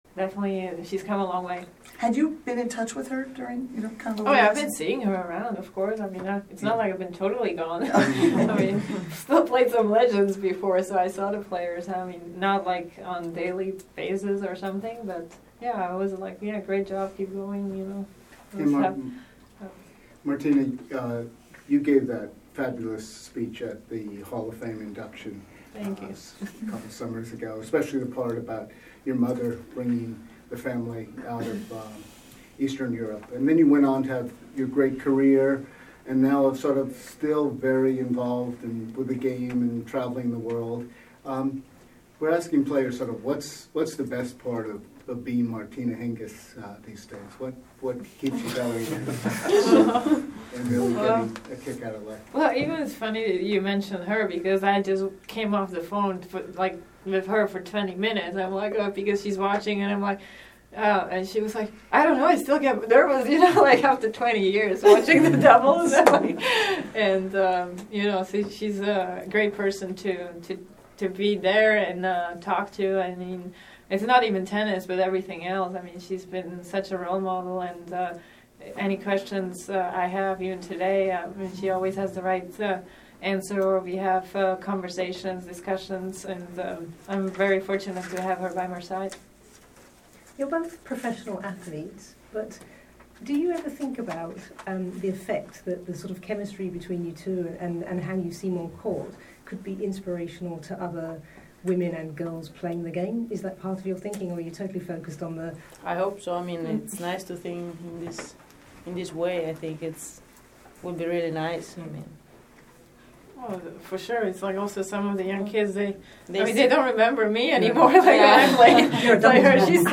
Flavia Pennetta in conferenza stampa:”Come faccio ad avere sempre belle compagne?